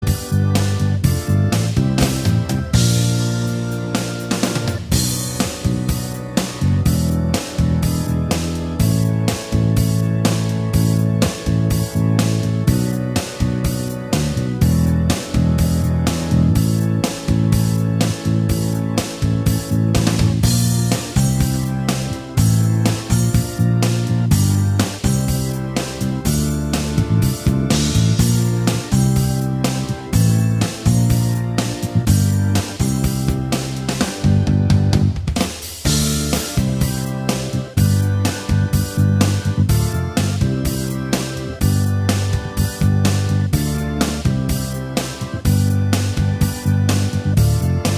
Minus All Guitars Soft Rock 5:17 Buy £1.50